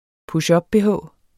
Udtale [ puɕˈʌb- ]